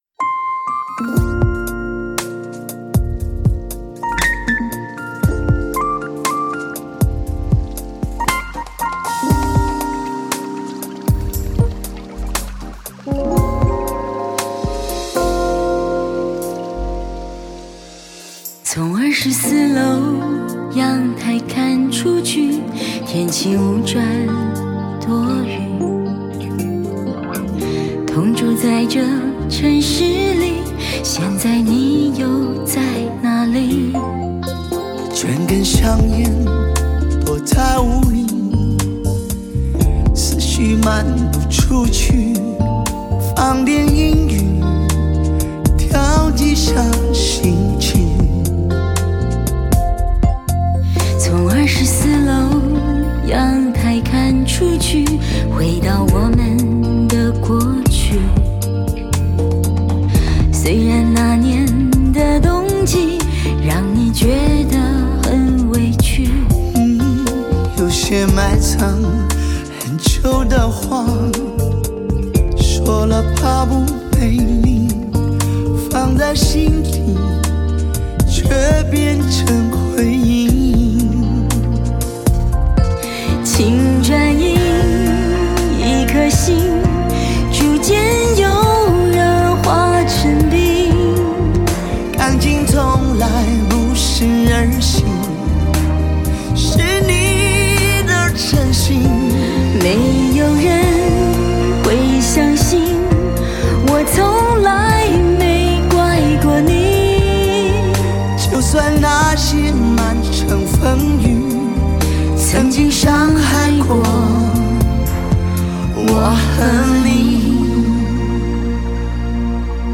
合唱